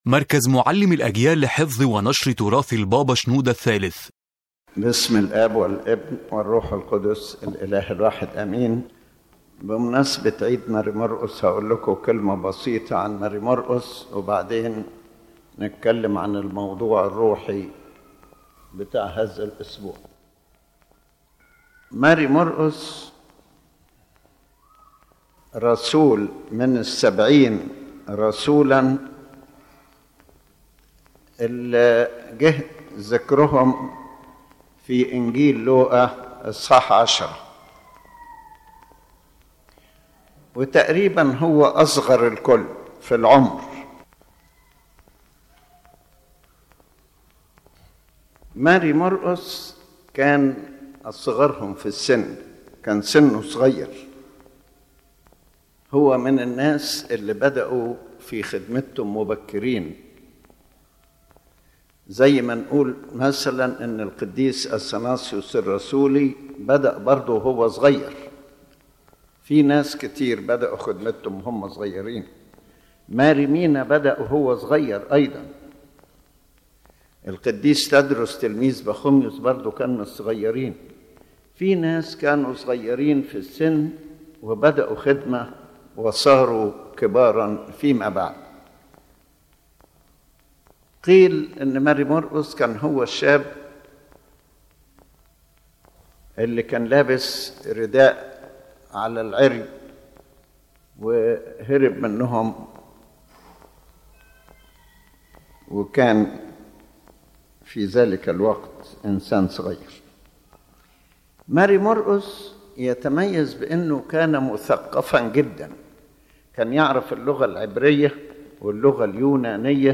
The lecture discusses the life of Saint Mark the Apostle and his great role in founding the Church of Alexandria and spreading the Christian faith. It also highlights the importance of his character in the history of the Coptic Orthodox Church and calls believers to imitate him and continue the mission he began.